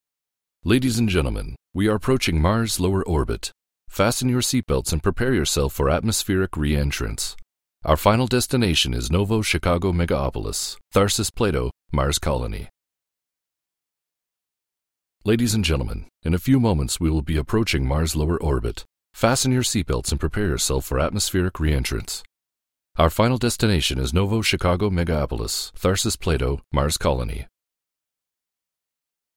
man4.mp3